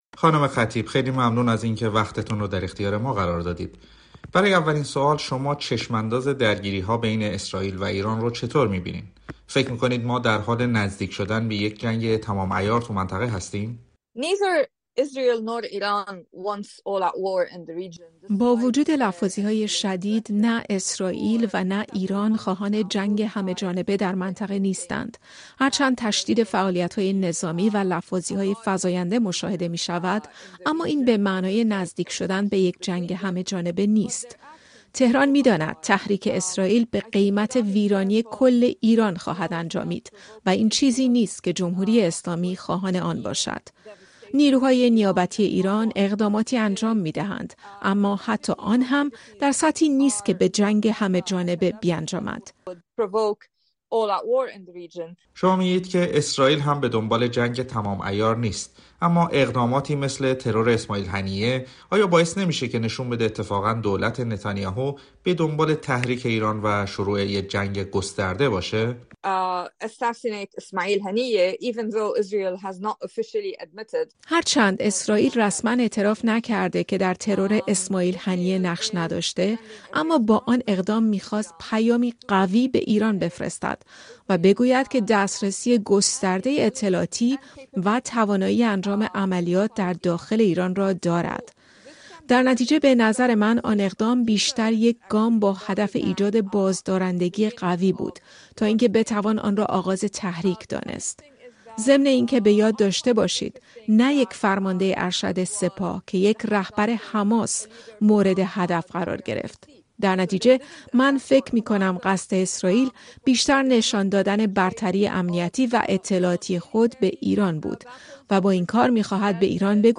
در گفتگو با رادیو فردا: اسرائيل دشمن مفیدی برای ایران است